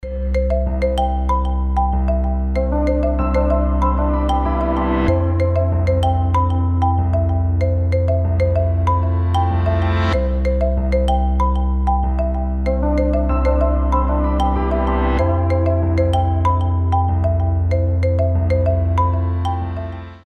• Качество: 320, Stereo
спокойные
без слов
красивая мелодия
нежные